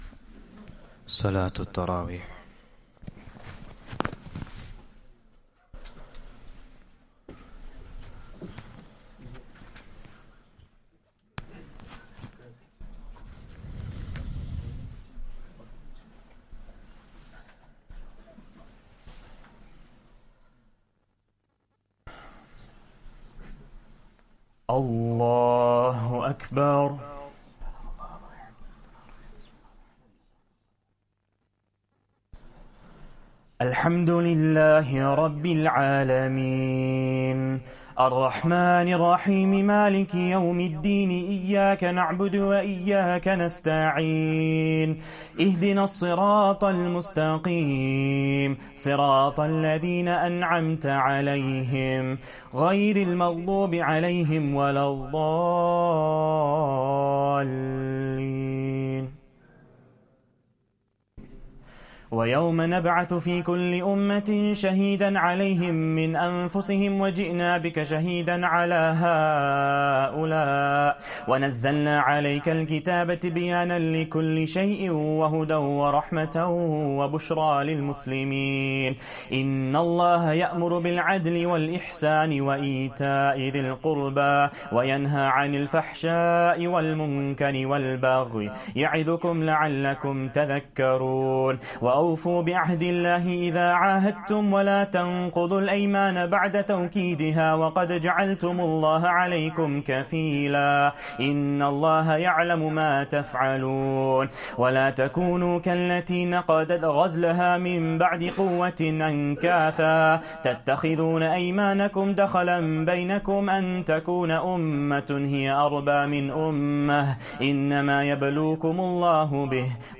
Taraweeh Prayer 12th Ramadan